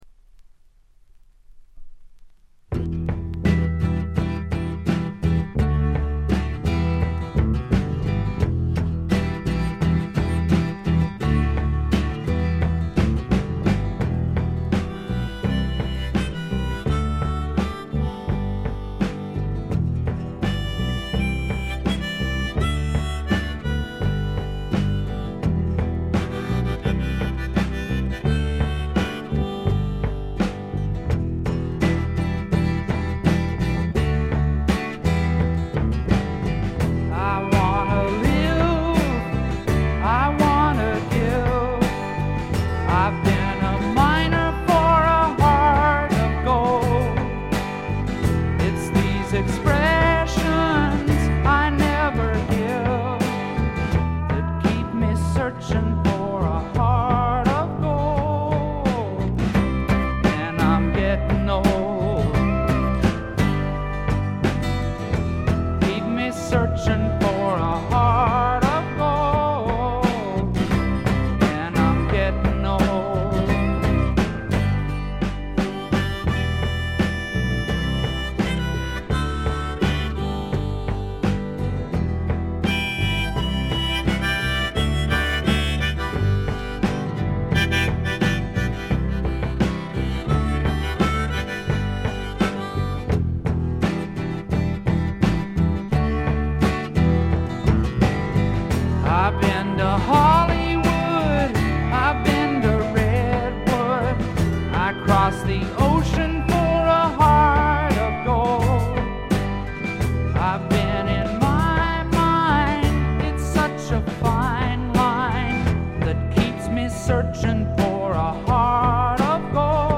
部分試聴ですがほとんどノイズ感無し。
試聴曲は現品からの取り込み音源です。
guitar, harmonica
pedal steel guitar
bass
drums
backing vocals